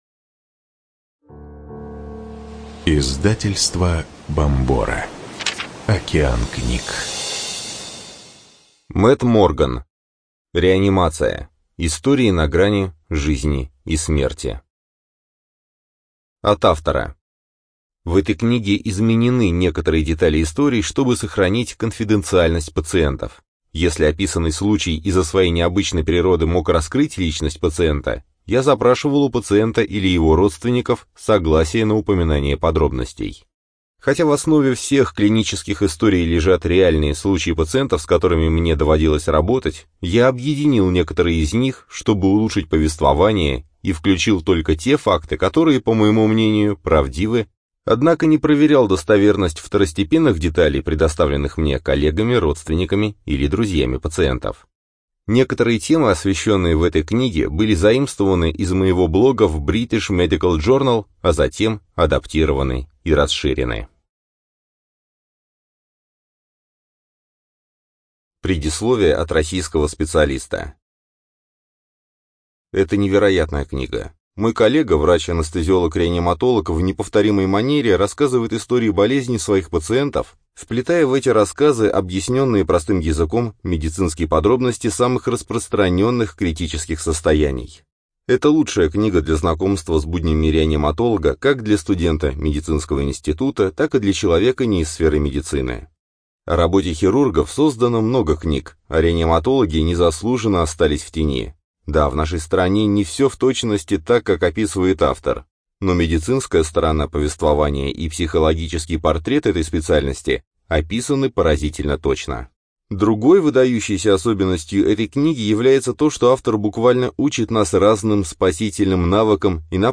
Студия звукозаписиБомбора